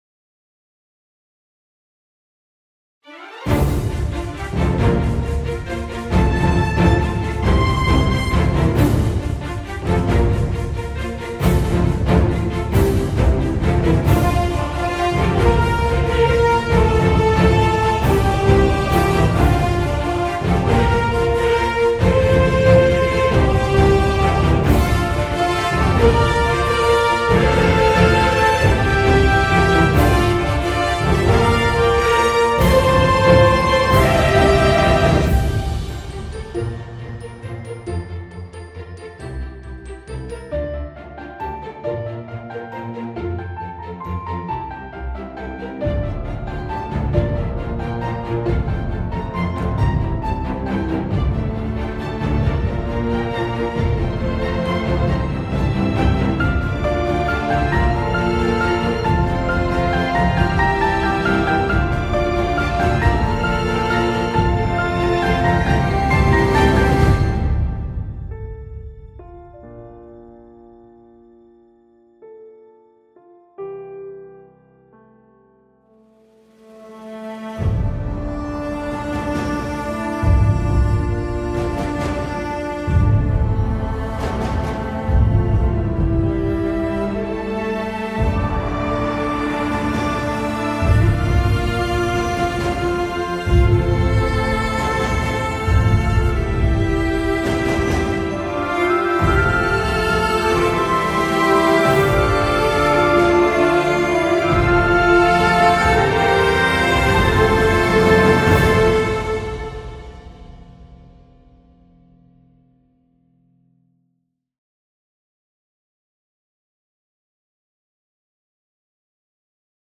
Film Music